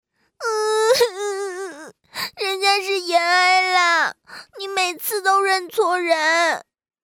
女声
少女萝莉-天命奇御-严哀